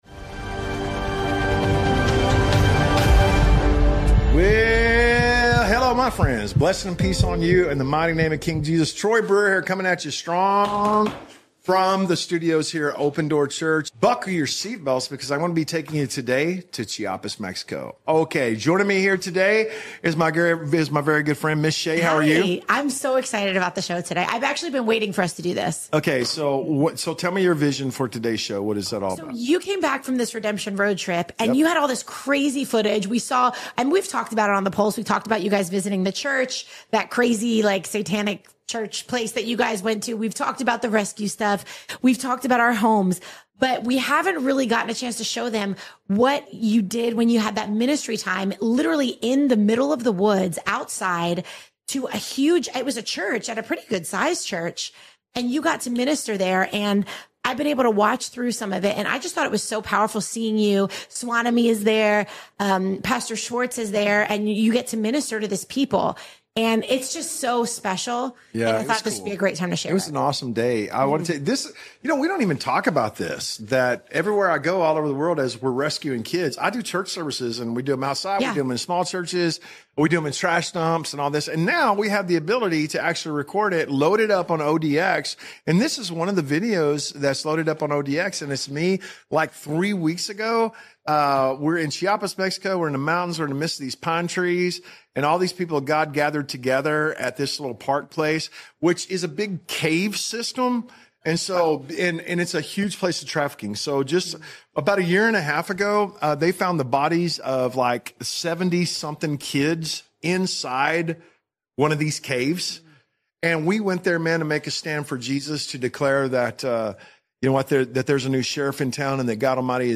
💥 Today’s episode is coming to you straight from Mexico, where we gathered with pastors and leaders hungry to see the Kingdom advance.